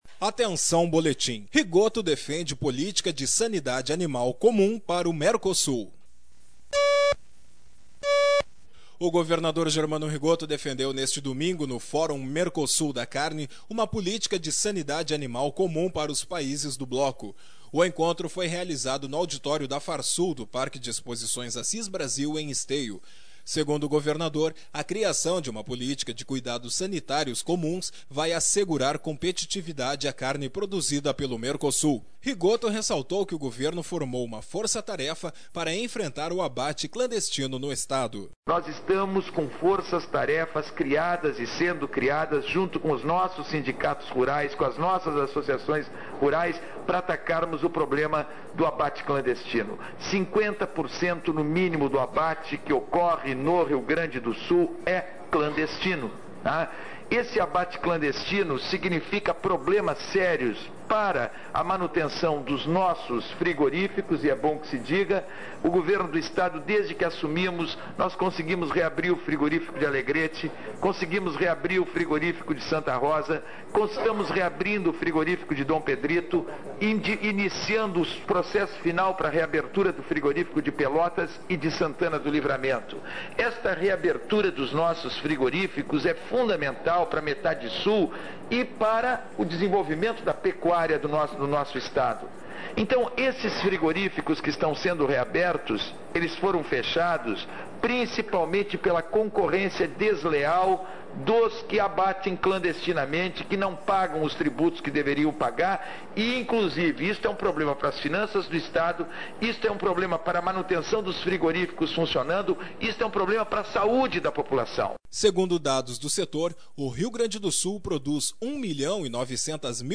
O governador Germano Rigotto defendeu neste domingo, no Fórum Mercosul da Carne, uma política de sanidade animal comum para os países do bloco. O encontro foi realizado no auditório da Farsul do Parque de Exposições Assis Brasil, em Esteio. sonora: gov